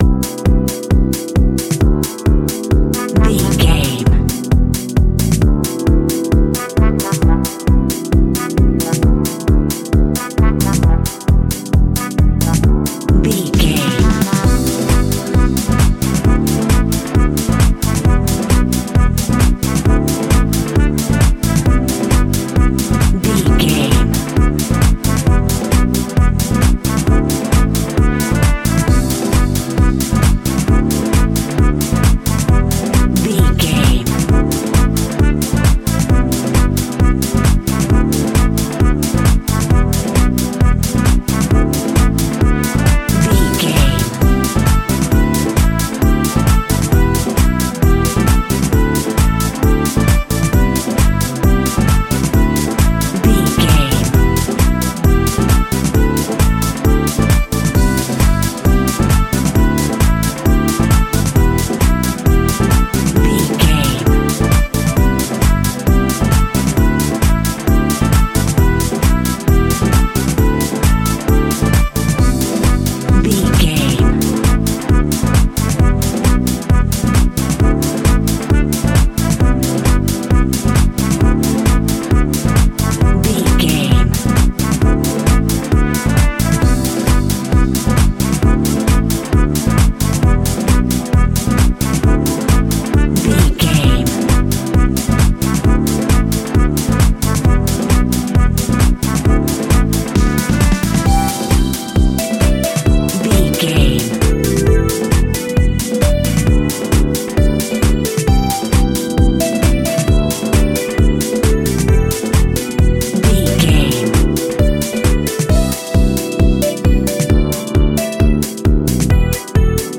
Aeolian/Minor
groovy
energetic
uplifting
hypnotic
drum machine
horns
bass guitar
funky house
nu disco
funky guitar
wah clavinet
synth bass
saxophones